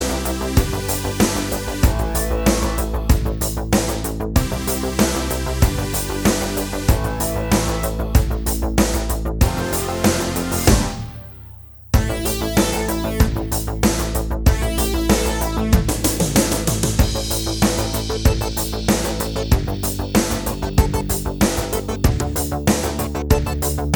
Minus All Guitars Pop (1980s) 4:02 Buy £1.50